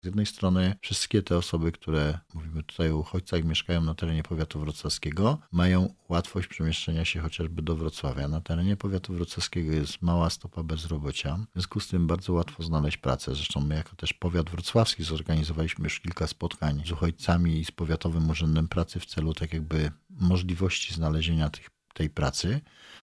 Starosta podkreśla również, że powiat wrocławski cieszy się dużym zainteresowaniem.